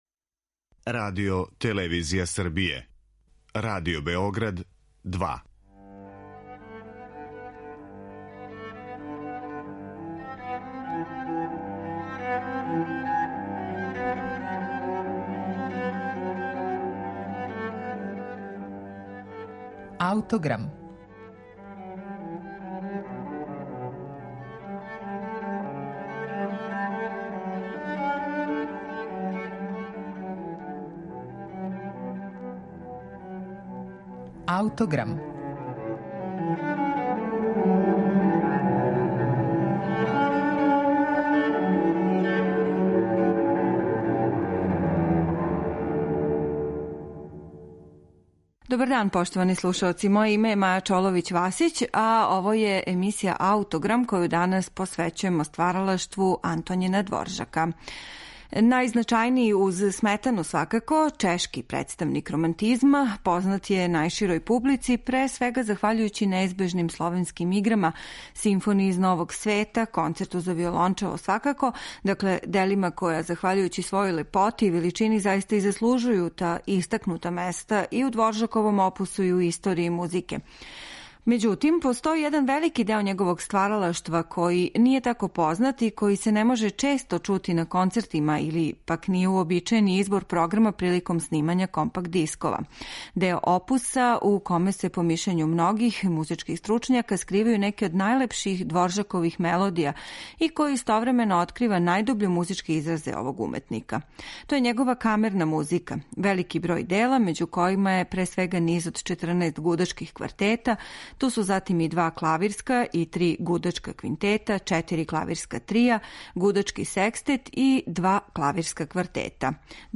многим хроматским и енхармонским модулацијама и променама
у окретању фолклорним темама и евидентном утицају народне музике